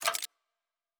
Weapon 14 Foley 1 (Flamethrower).wav